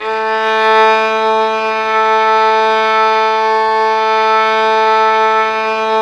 Index of /90_sSampleCDs/Roland L-CD702/VOL-1/STR_Violin 4 nv/STR_Vln4 _ marc
STR VLN BO01.wav